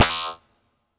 Futuristic Device Glitch (1).wav